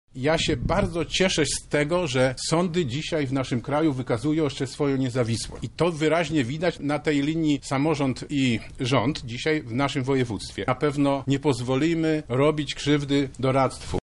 Tak sytuacje komentuje Sławomir Sosnowski Marszałek Województwa Lubelskiego.